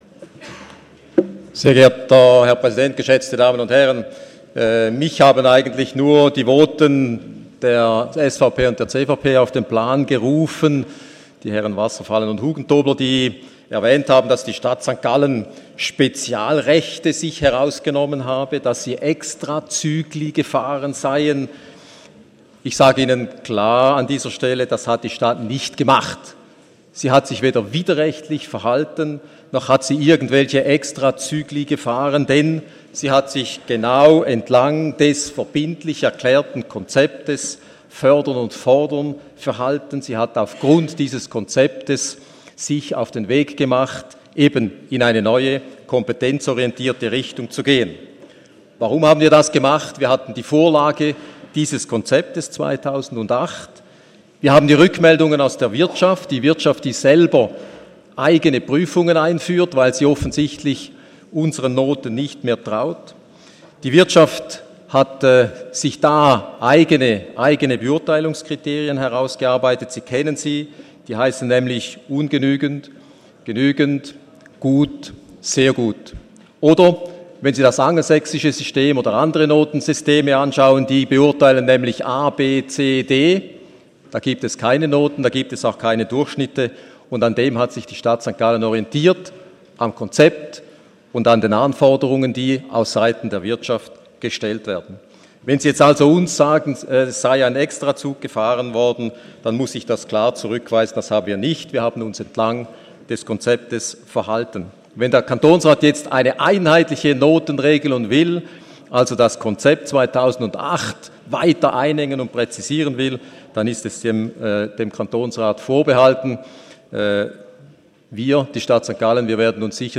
25.4.2017Wortmeldung
Session des Kantonsrates vom 24. und 25. April 2017